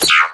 ui_select_reject.wav